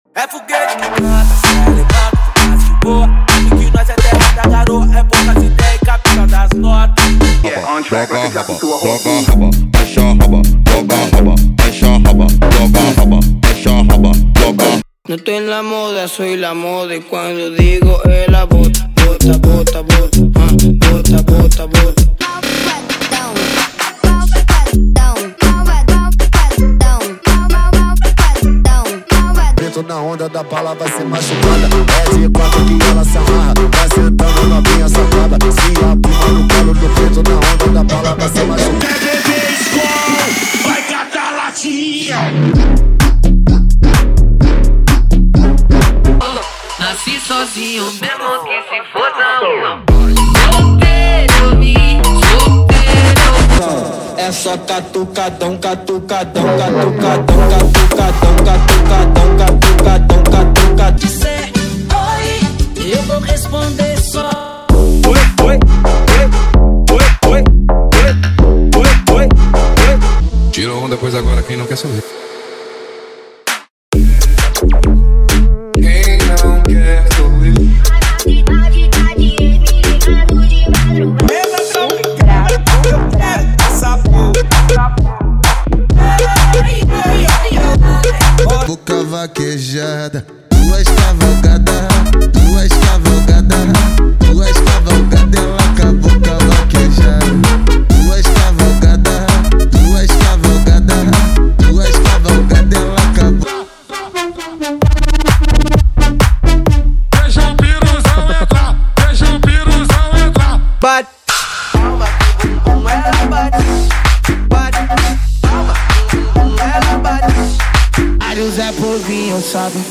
• Eletro Funk = 50 Músicas
• Em Alta Qualidade